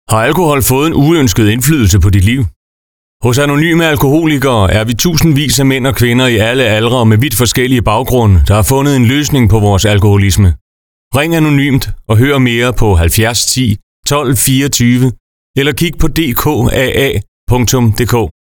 Informationsudvalget i Region Midtjylland har fået lavet nogle radiospots, der kort fortæller om AA og hvor du kan finde AA.
Der er allerede blevet brugt af lokalradiostationer.